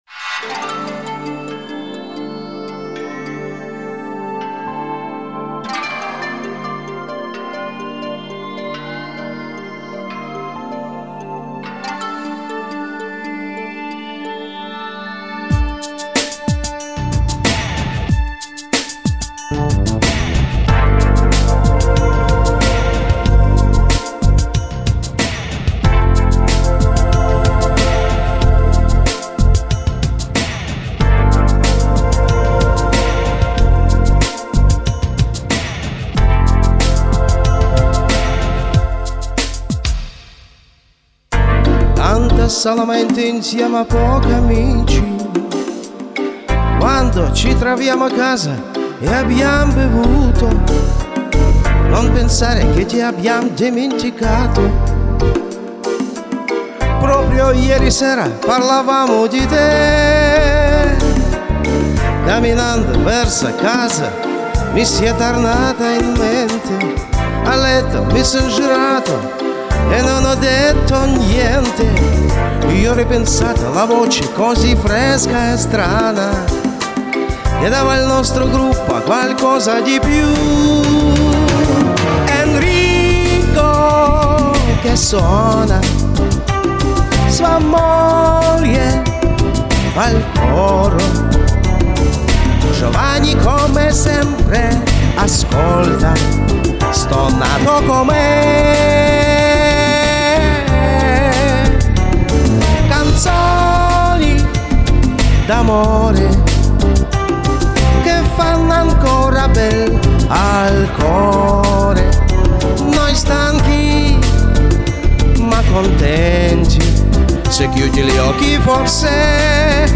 От чего Вы поленились записать второй голос???!!!